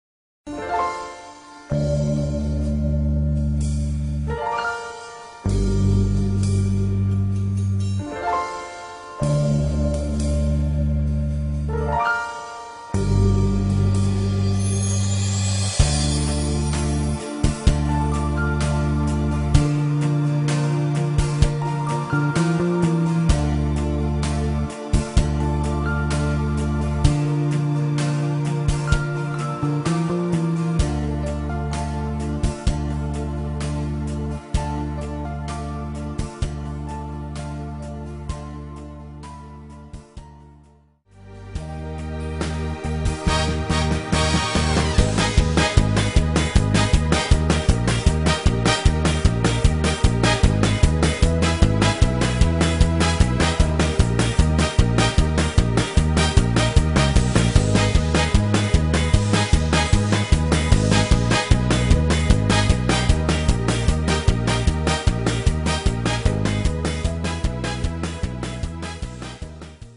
פלייבק או מנגינה , העיקר ללא מילים.